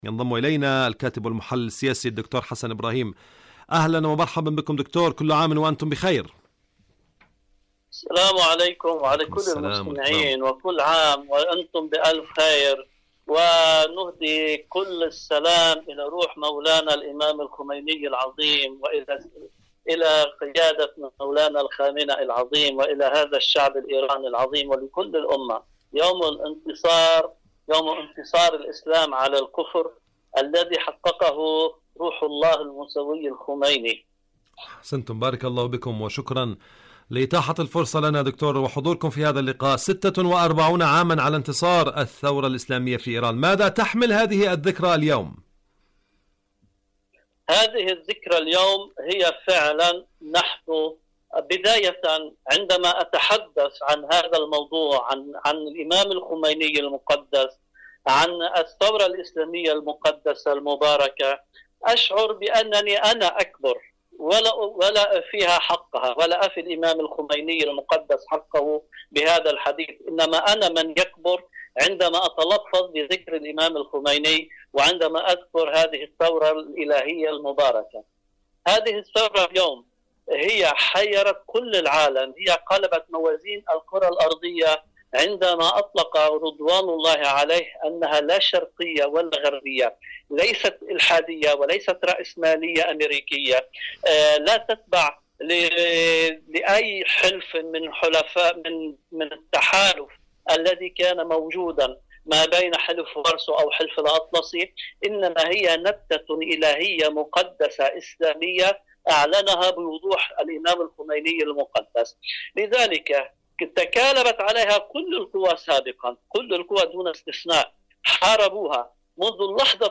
برنامج فلسطين اليوم مقابلات إذاعية